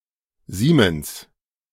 Siemens AG (German pronunciation: [ˈziːməns]
De-Siemens.ogg.mp3